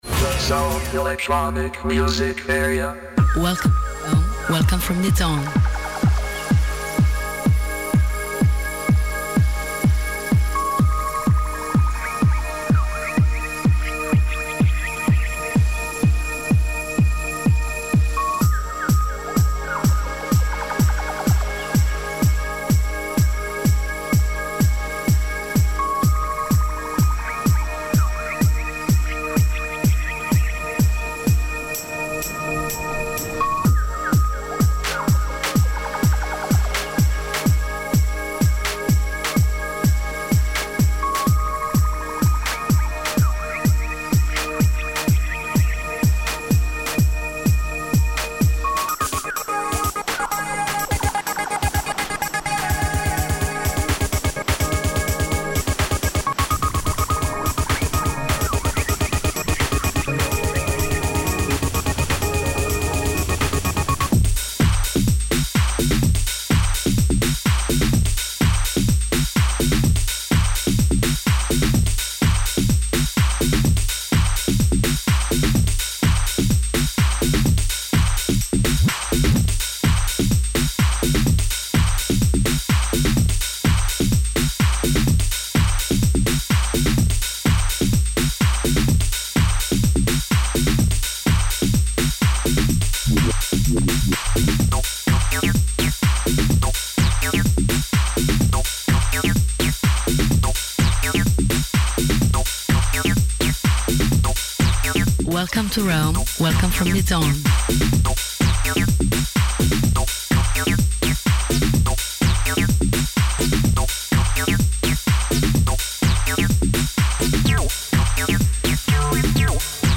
ON-AIR LIVE SHOW
For all the Electronic/Electro music lovers!
60-minute electronic atmospheres